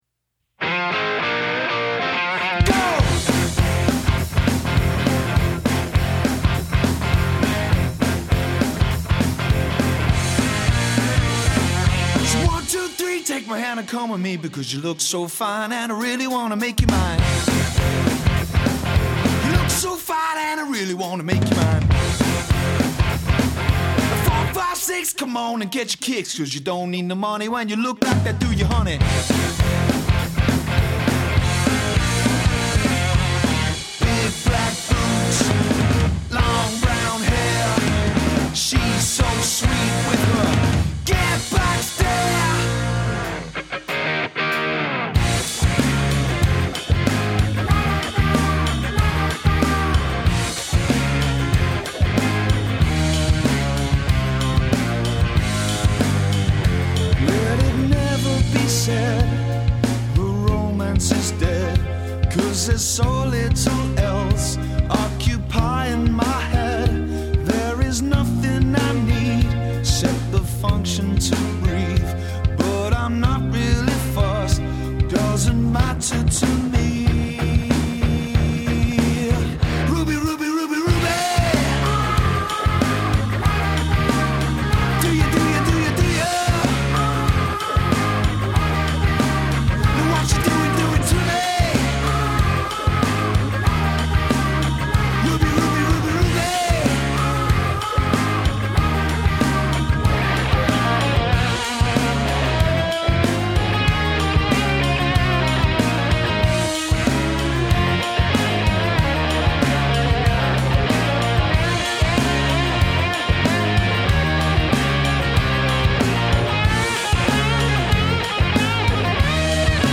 • Indie hits performed by high-energy musicians
• 4-piece
Male Vocals / Guitar, Guitar, Bass, Drums